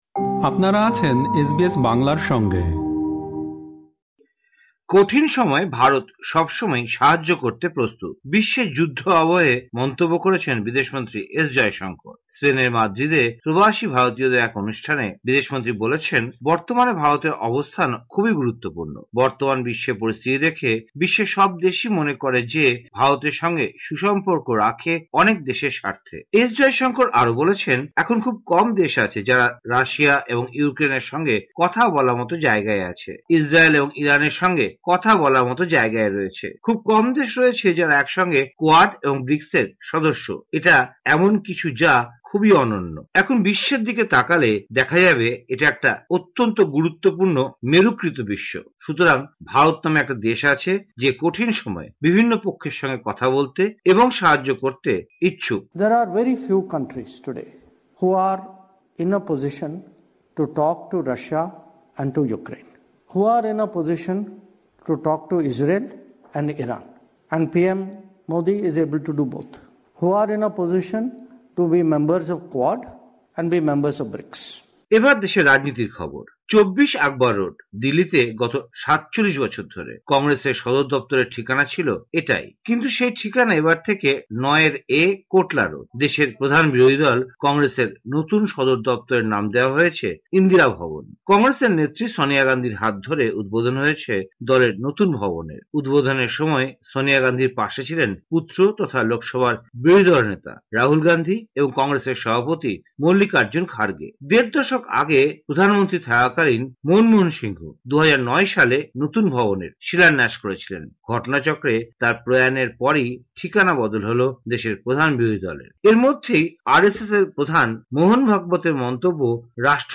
ভারতের সাম্প্রতিক খবর, ২০ জানুয়ারি, ২০২৫